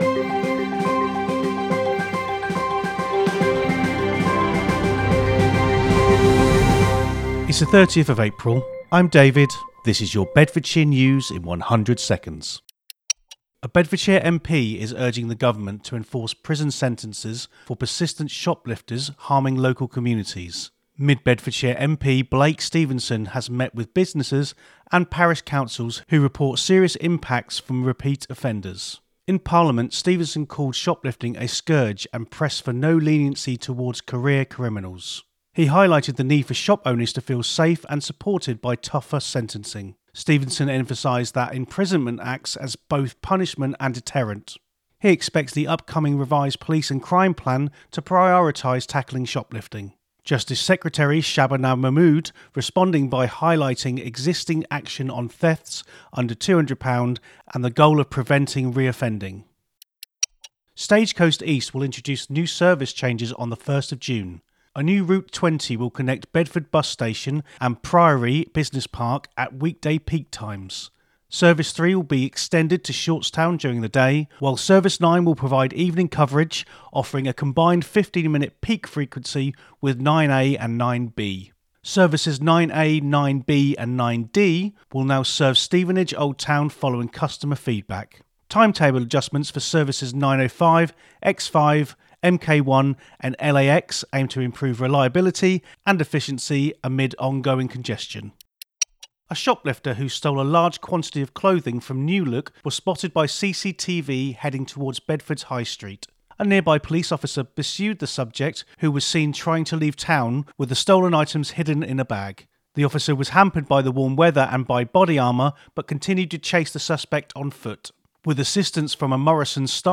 A free audio news roundup for Bedford and the greater Bedfordshire area, every weekday.